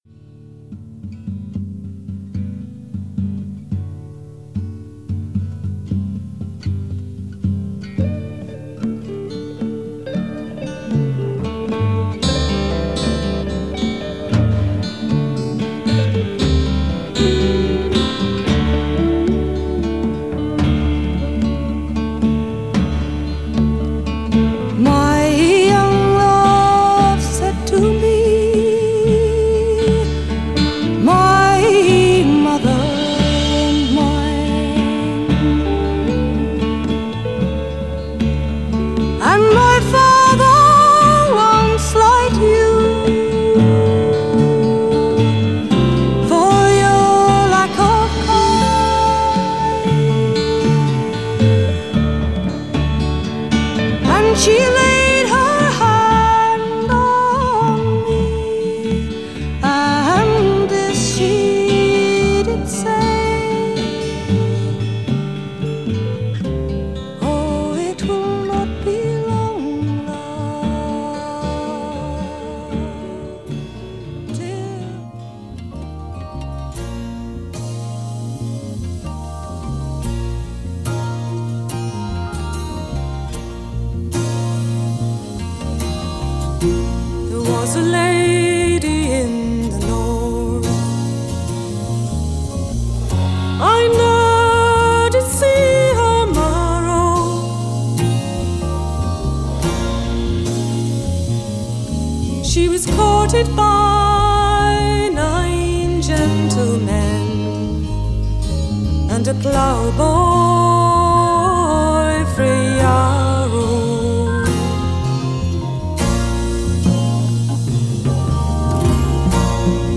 to a lot of British folk music.